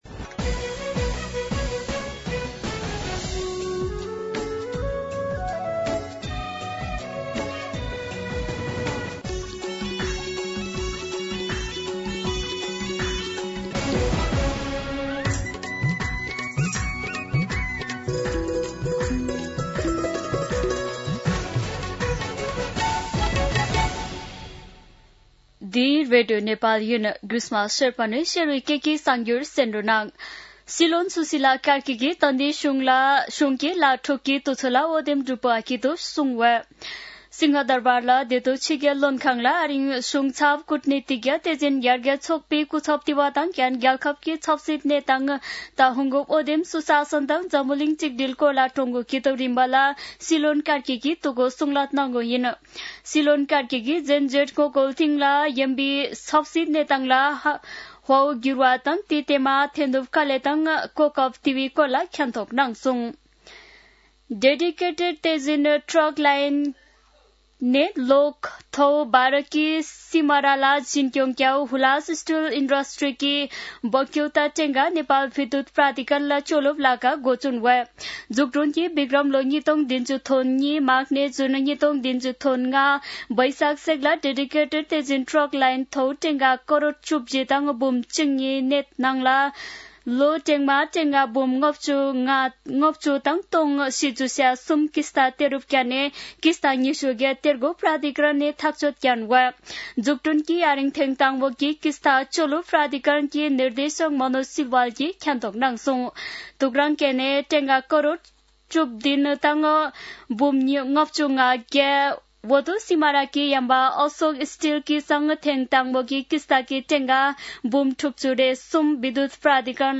शेर्पा भाषाको समाचार : ३१ असोज , २०८२
Sherpa-News-5.mp3